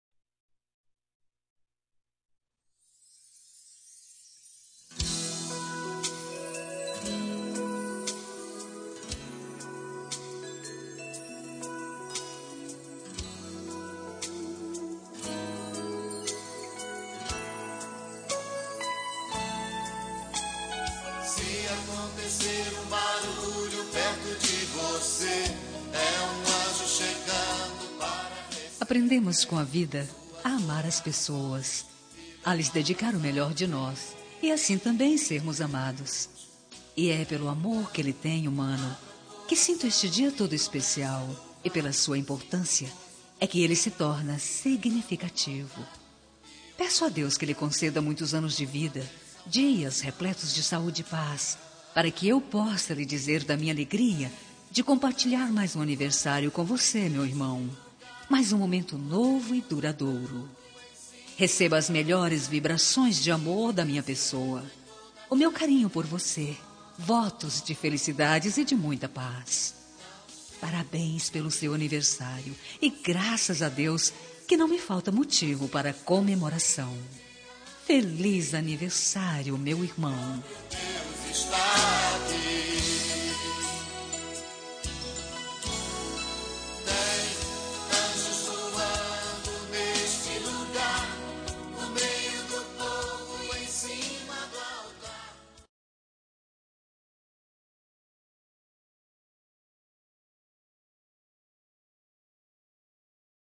Telemensagem de Aniversário de Irmão – Voz Feminina – Cód: 1702 – Religiosa